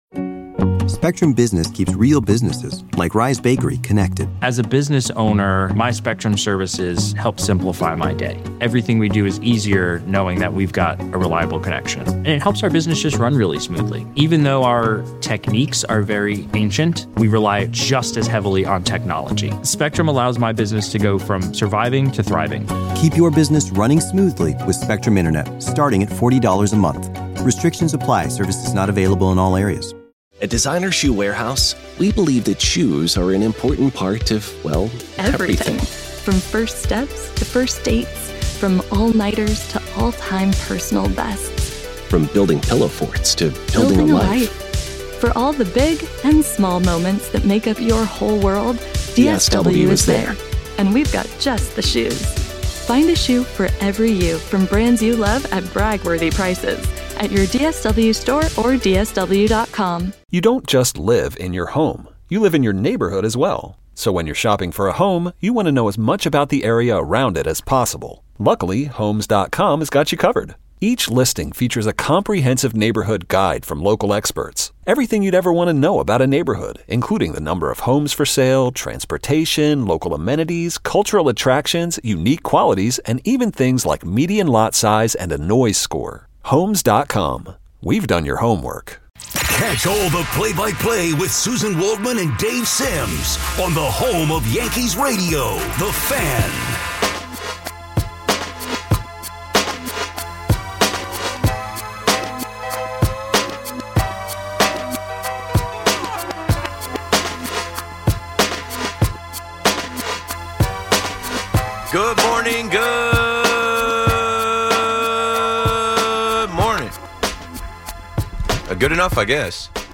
Rooted in systemic and ancestral wisdom, this conversation explores …